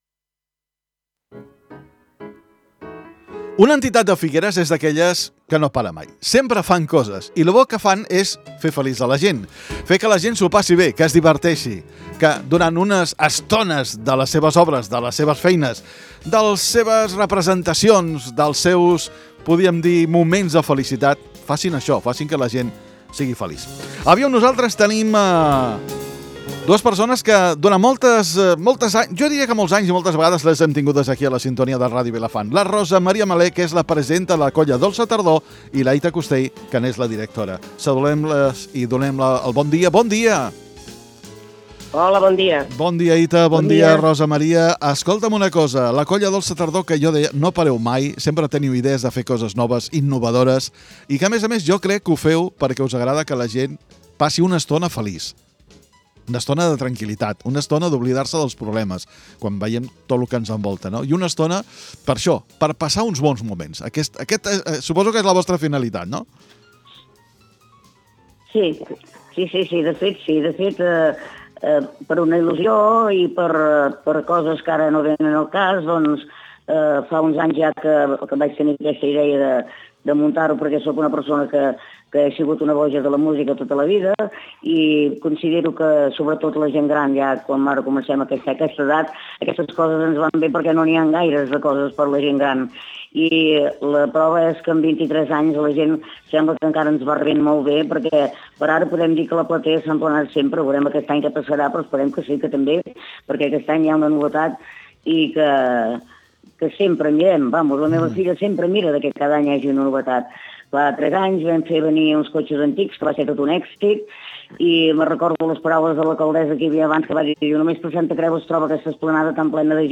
LVDM - ENTREVISTA COLLA DOLÇA TARDOR FINS 17 NOVEMBRE~0.mp3